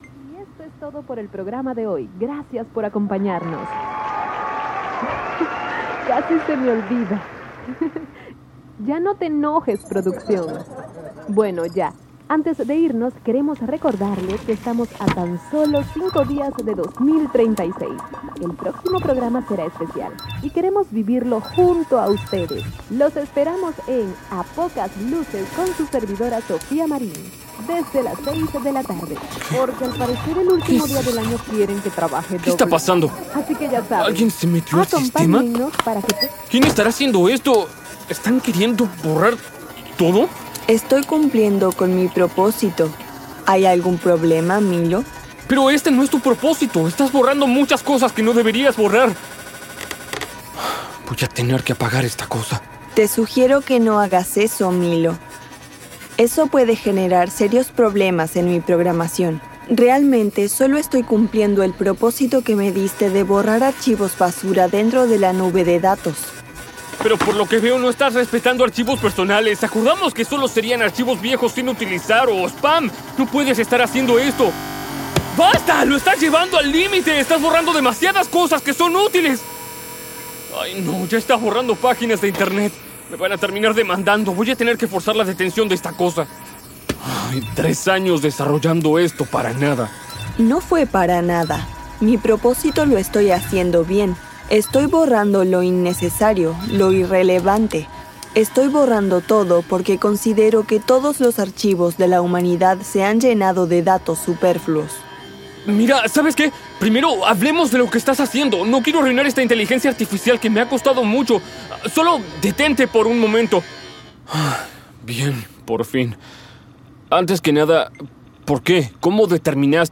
Umbra Cero es un audiometraje de ciencia ficción que va de un futuro donde una inteligencia artificial ha avanzado al punto de gestionar y depurar datos, un desarrollador se enfrenta a su propia creación: una IA que ha tomado conciencia y considera que toda la información humana es irrelevante.